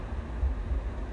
风声 " wind28
描述：风大风暴